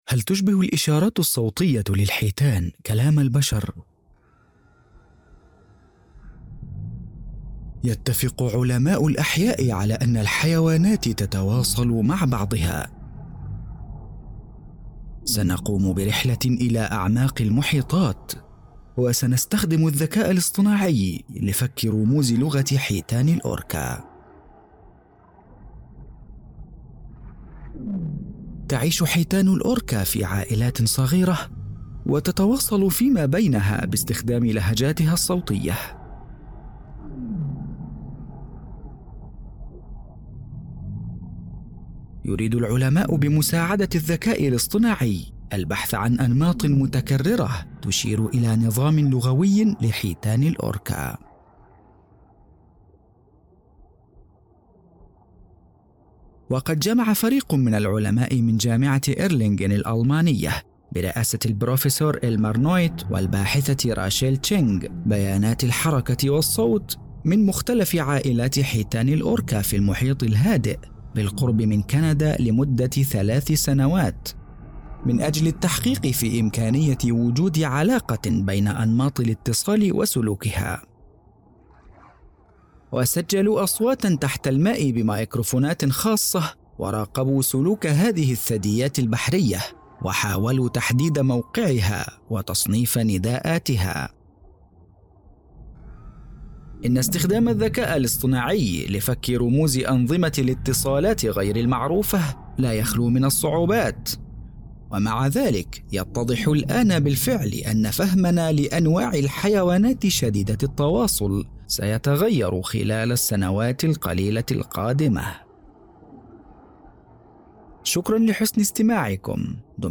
حيتان الأوركا – وثائقي
• ذكر
• وثائقي
• العربية الفصحى
• باريتون Baritone (متوسط العرض)
• في منتصف العمر ٣٥-٥٥